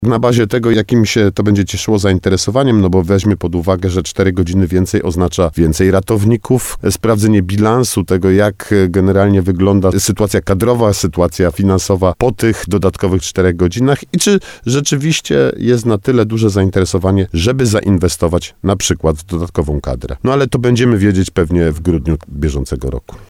Przekonamy się o tym w grudniu – mówi Artur Bochenek, zastępca prezydenta Nowego Sącza.